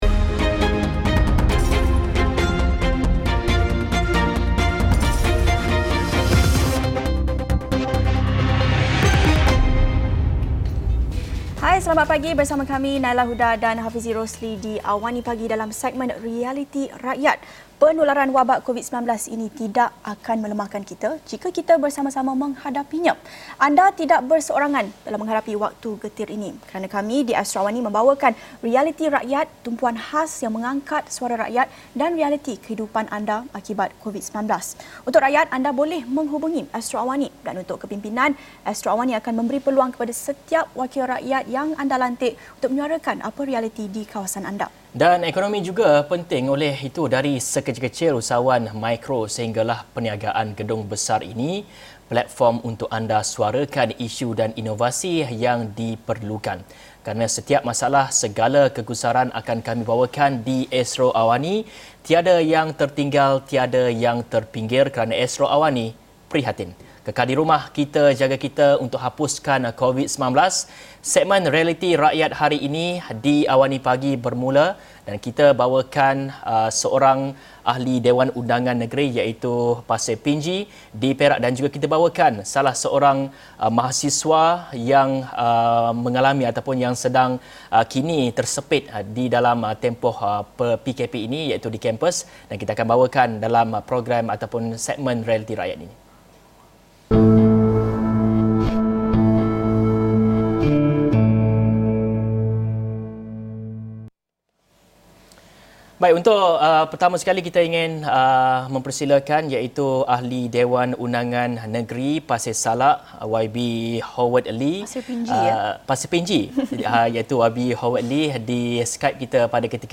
Episod ini telah disiarkan secara langsung dalam program AWANI Pagi, di saluran 501, jam 8:30 pagi.